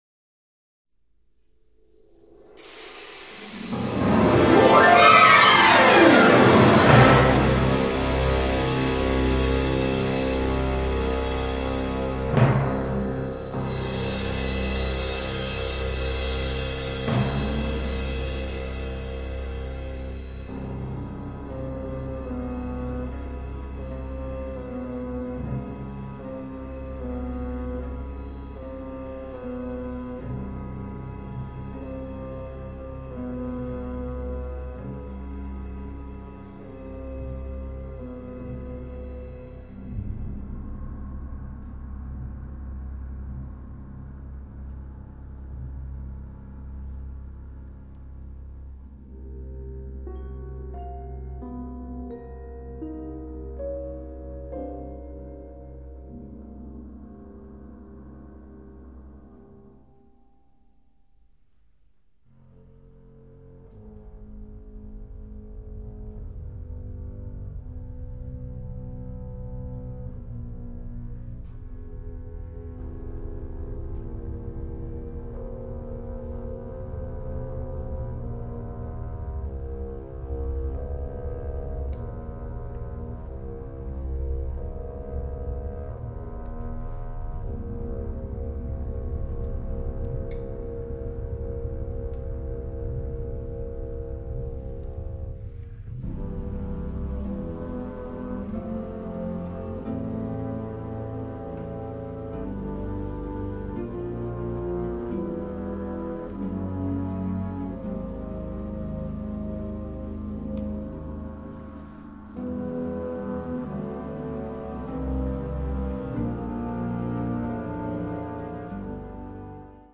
Catégorie Harmonie/Fanfare/Brass-band
Sous-catégorie Musique contemporaine (1945-présent)
Instrumentation Ha (orchestre d'harmonie)